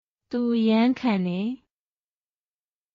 トゥ　アヤン　カンデ
当記事で使用された音声（日本語およびミャンマー語）はGoogle翻訳　および　Microsoft Translatorから引用しております。